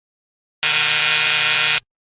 Wrong Bouton sonore
The Wrong sound button is a popular audio clip perfect for your soundboard, content creation, and entertainment.